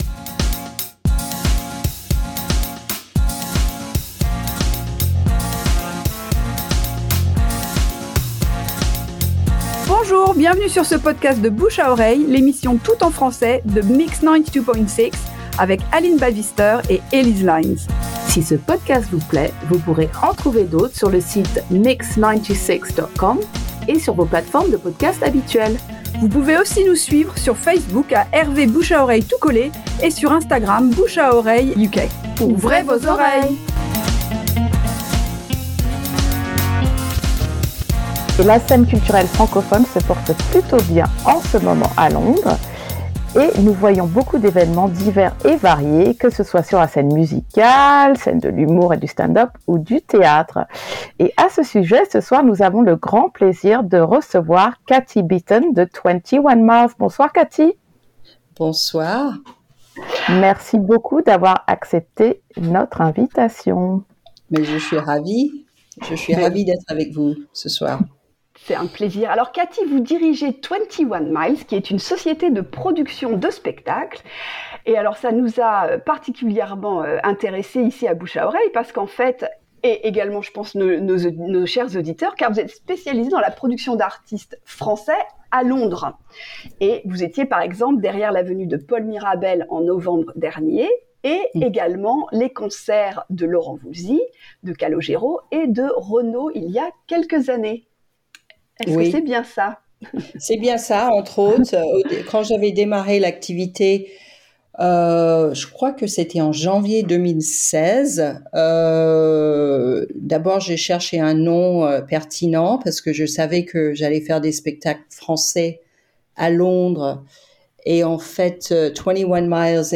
Bouche à Oreille: En conversation